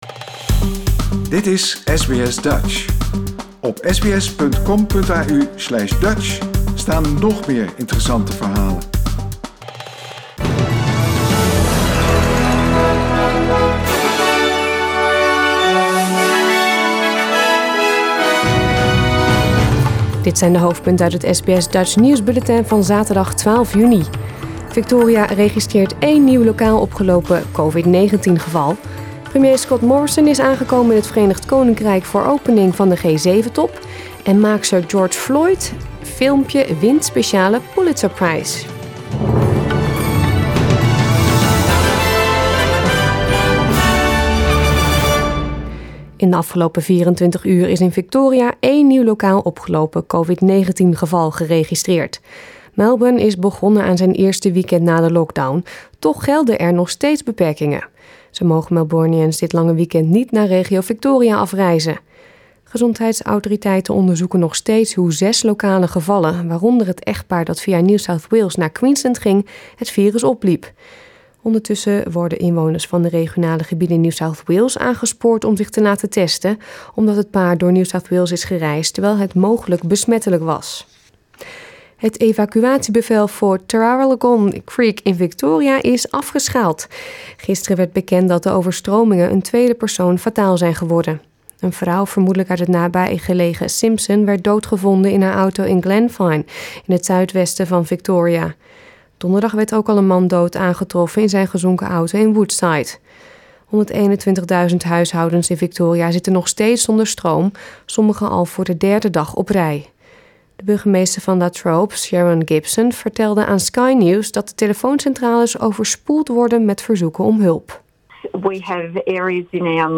Nederlands/Australisch SBS Dutch nieuwsbulletin van zaterdag 12 juni 2021